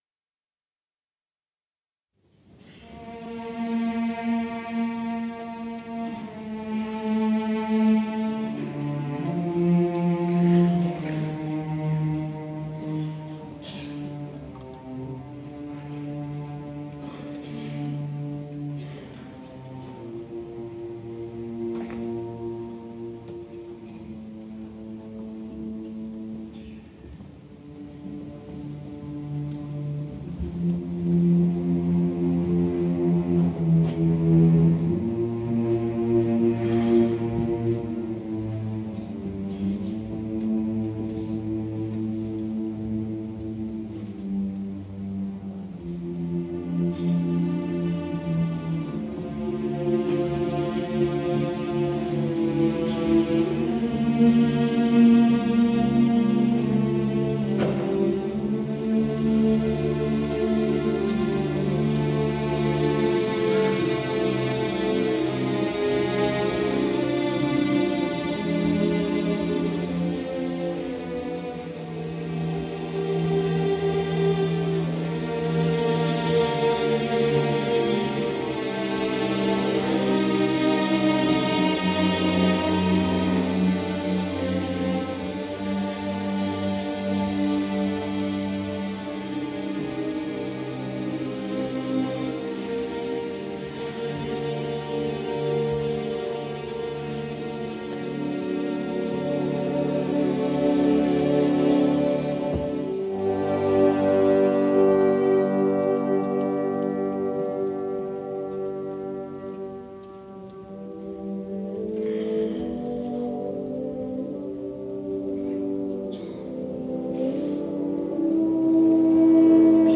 前奏曲 C大调。先由管弦乐奏出《名歌手动机》。再由长笛开始，奏出表情丰富的柔和旋律。